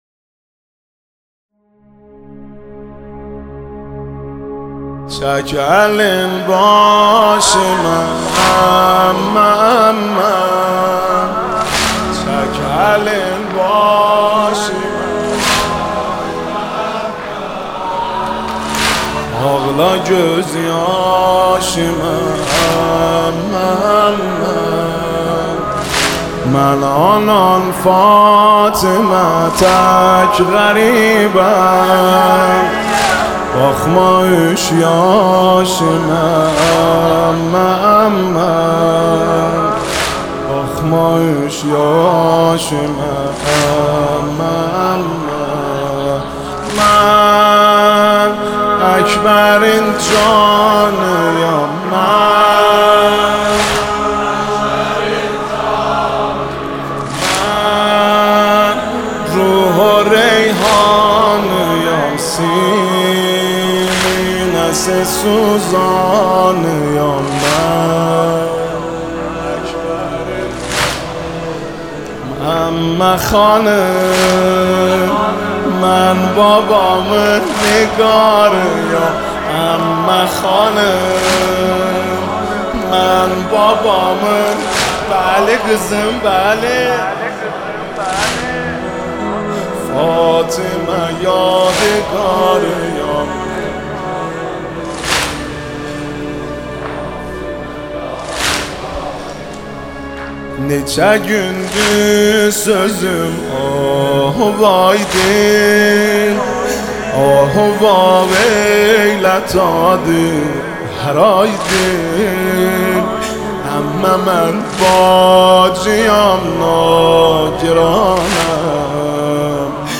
نماهنگ ترکی جدید
با نوای دلنشین